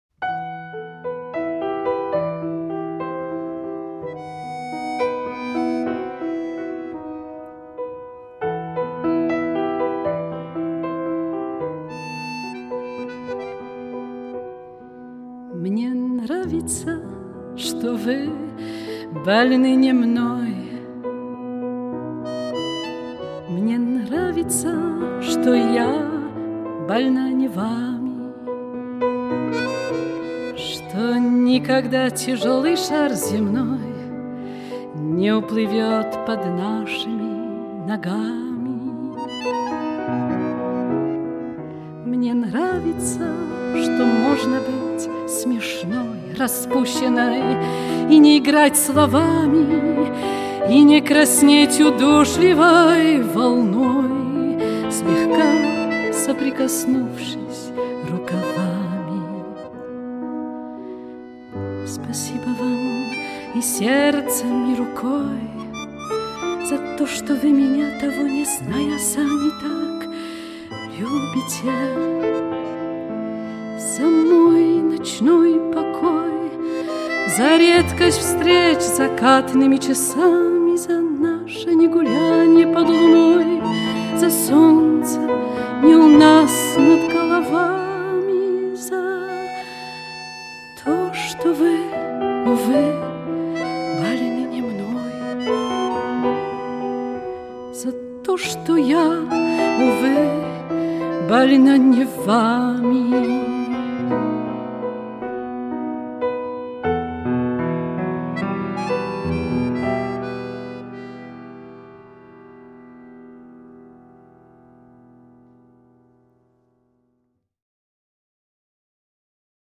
playing piano and accordion.